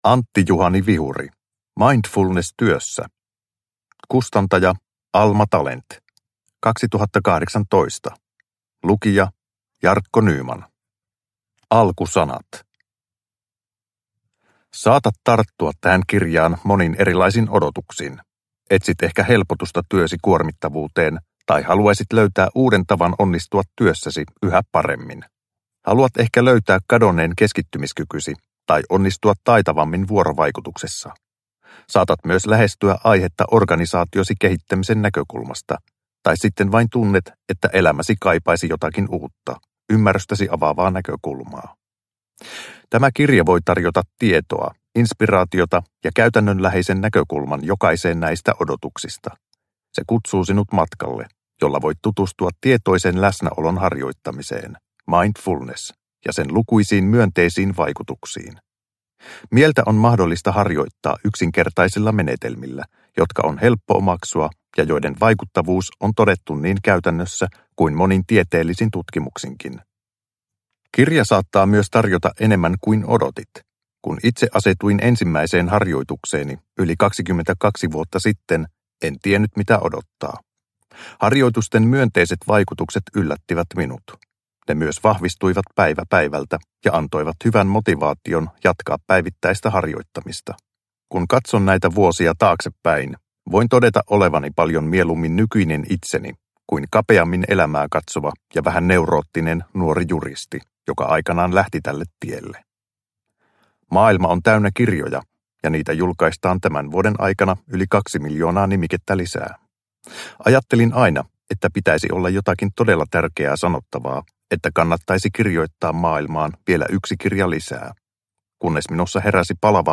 Mindfulness työssä – Ljudbok – Laddas ner